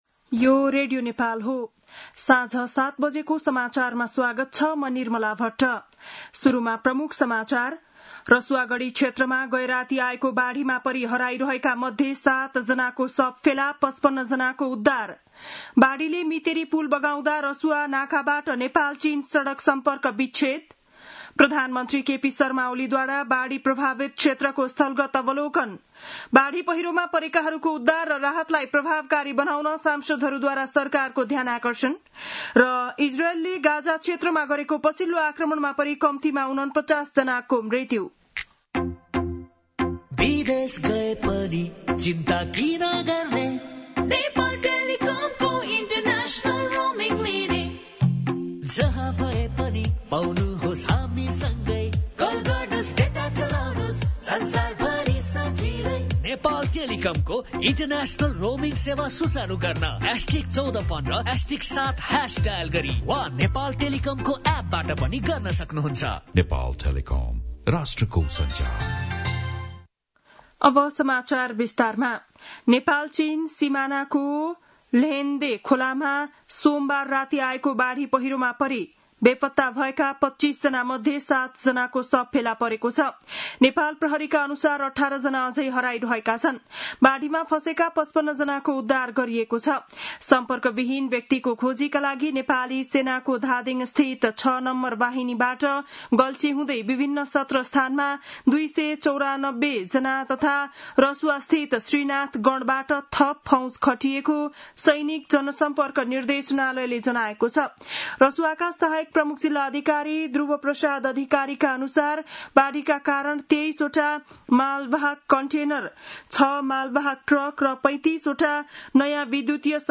बेलुकी ७ बजेको नेपाली समाचार : २४ असार , २०८२
7-pm-nepali-news-3-24.mp3